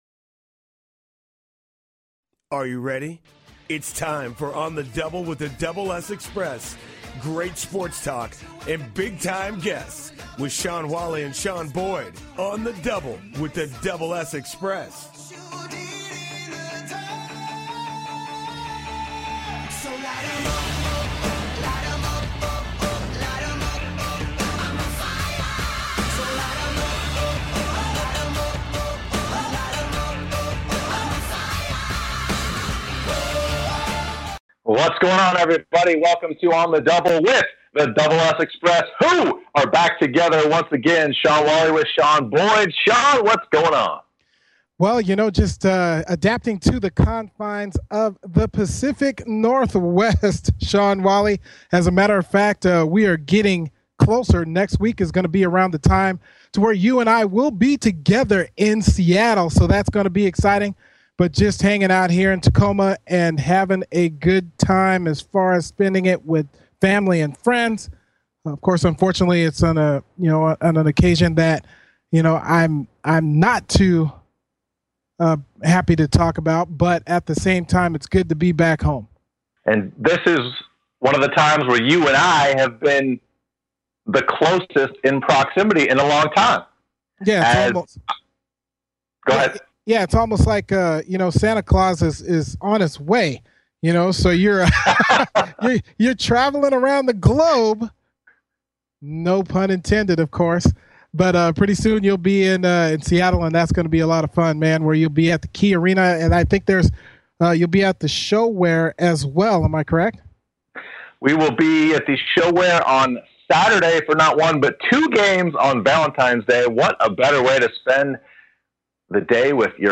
Talk Show Episode
On the Double with The Double S Xpress sports talk show; Sports Broadcasting; Play-by-Play; Analysis; Commentary; Insight; Interviews; Public Address Mission: To provide the best play-by-play & analysis of all sports in the broadcasting world.